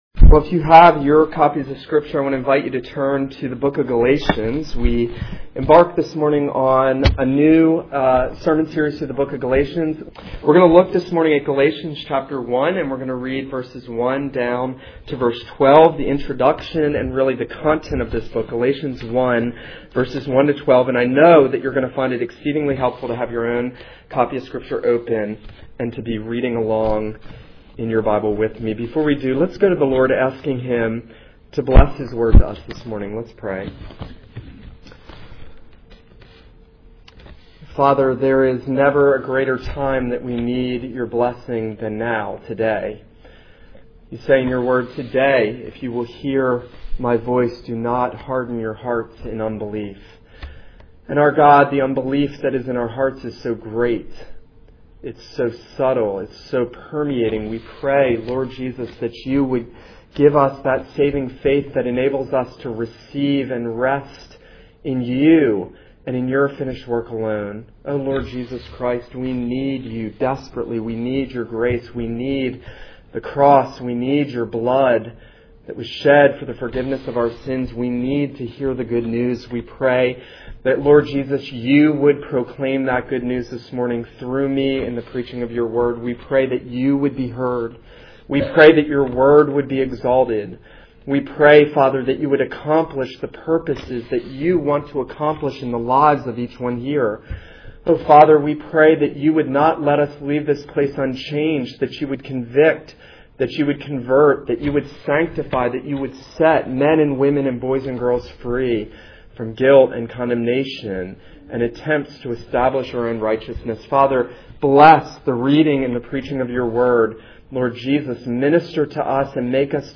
This is a sermon on Galatians 1:1-12.